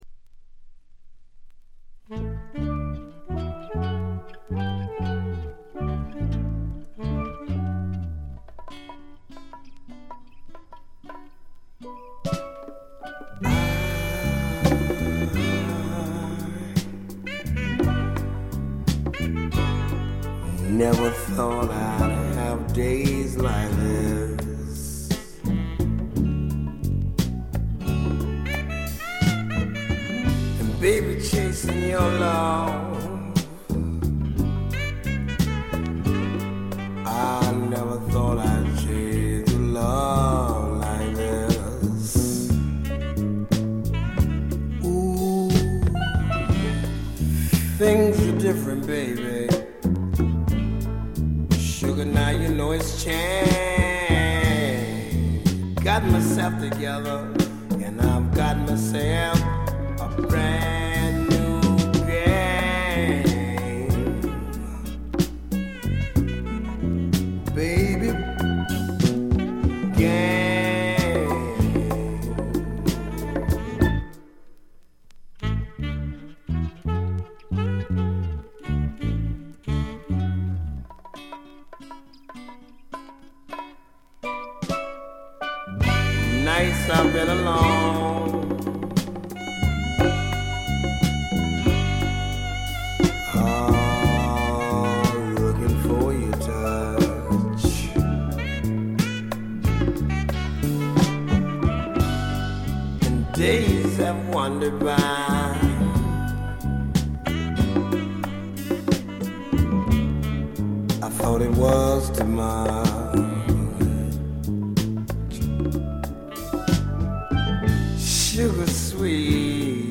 ほとんどノイズ感無し。
試聴曲は現品からの取り込み音源です。
Steel Drums, Percussion
Recorded at Conway Recorders Co., Hollywood, Aug. 1976.